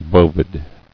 [bo·vid]